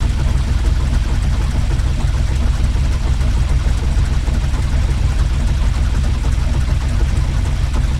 igeneratorOperate.ogg